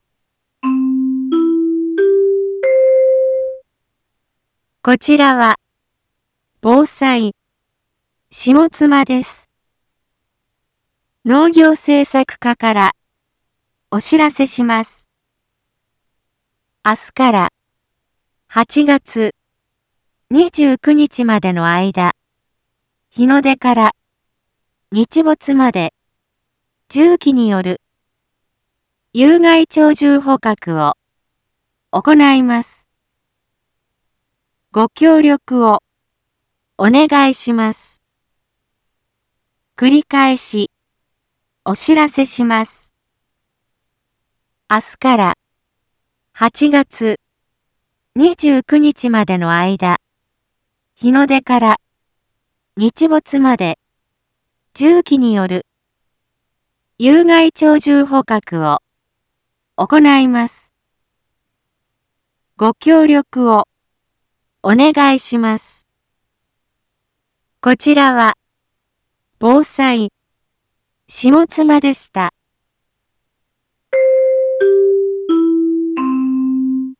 一般放送情報
Back Home 一般放送情報 音声放送 再生 一般放送情報 登録日時：2024-07-01 18:01:18 タイトル：有害鳥獣捕獲についてのお知らせ インフォメーション：こちらは、ぼうさい、しもつまです。